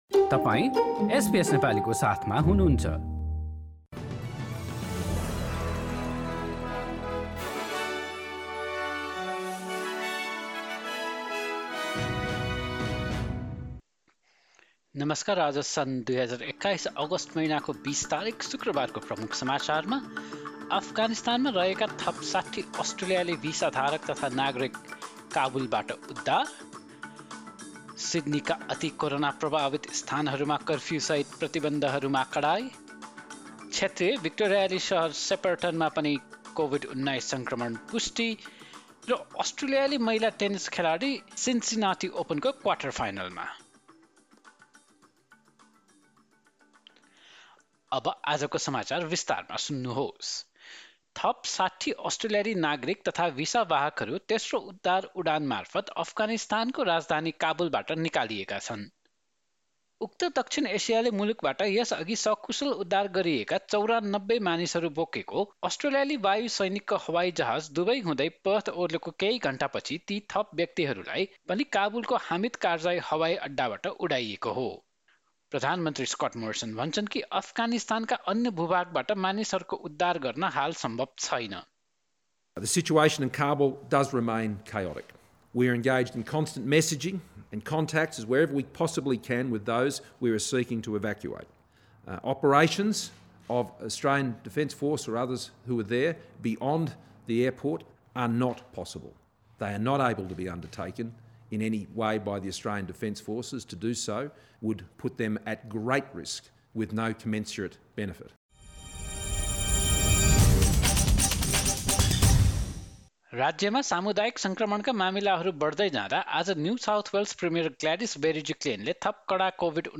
एसबीएस नेपाली अस्ट्रेलिया समाचार: शुक्रवार २० अगस्ट २०२१